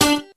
neptunesguitar3.wav